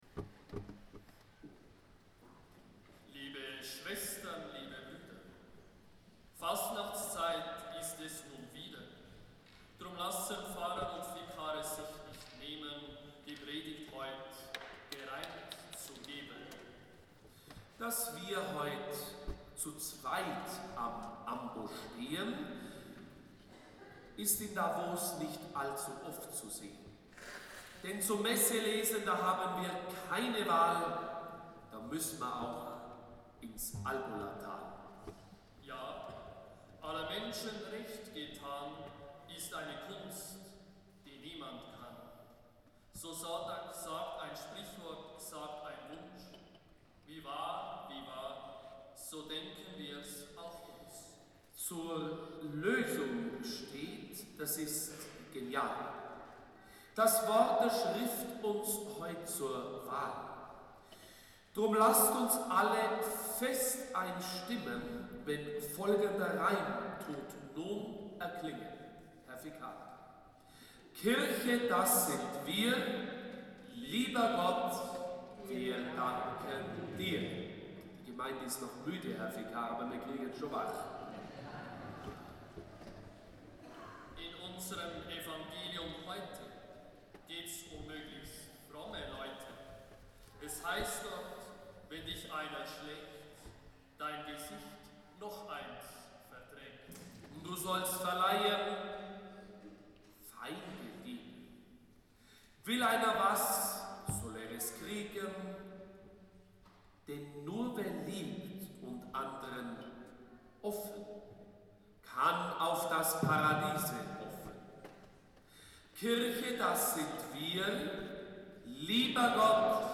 Fasnachts-Predigt
Fasnachtspredigt.mp3 (14,1 MiB)